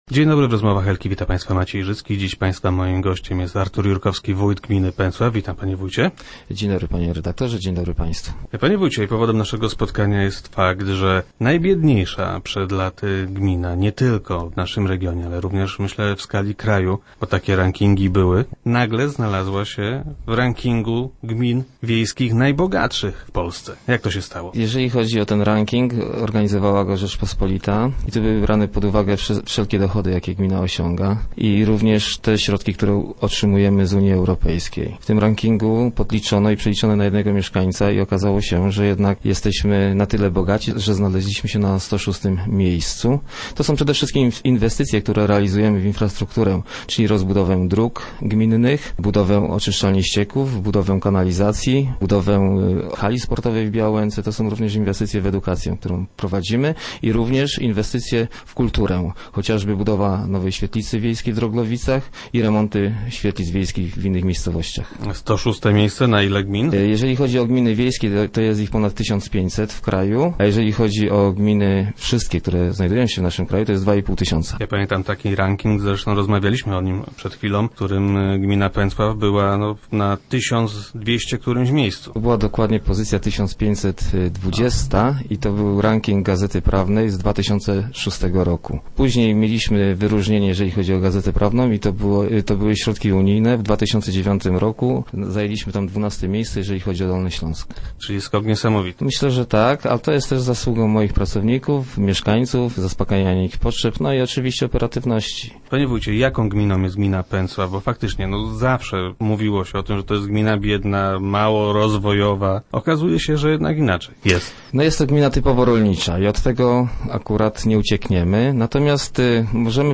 Gościem Rozmów Elki był Artur Jurkowski, wójt Pęcławia.